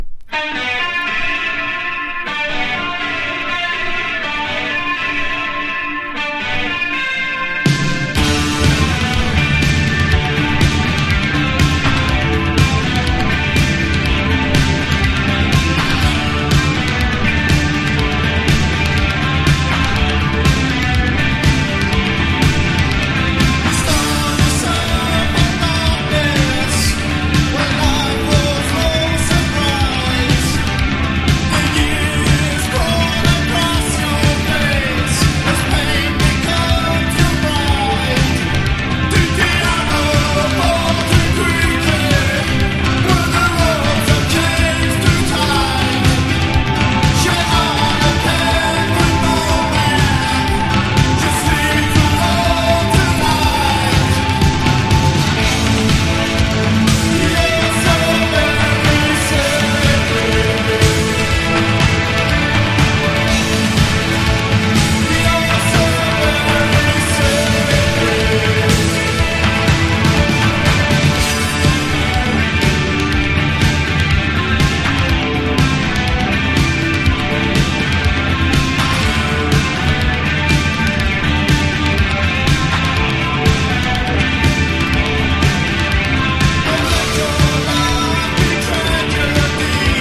メタリックなギターにヘヴィーなリズム、そこに独特のヴォーカルが入り唯一無二の世界観を展開した
# POST PUNK